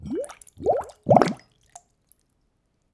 bubbleglorp.wav